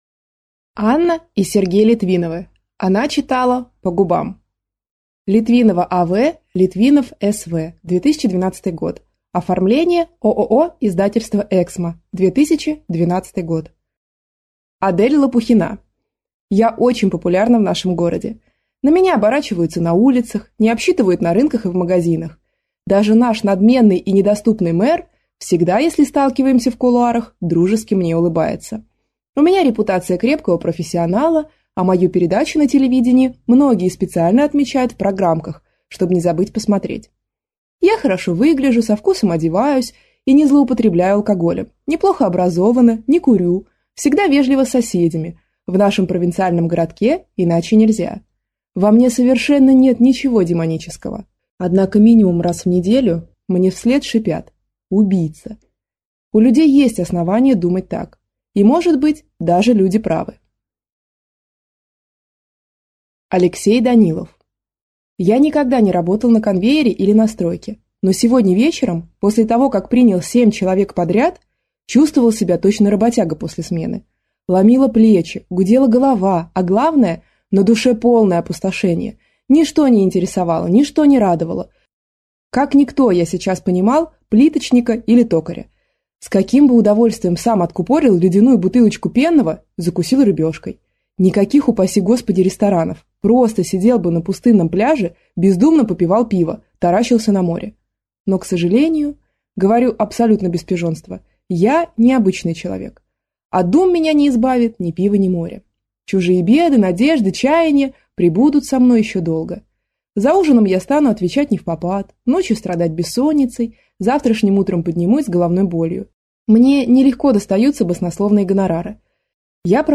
Аудиокнига Она читала по губам | Библиотека аудиокниг
Прослушать и бесплатно скачать фрагмент аудиокниги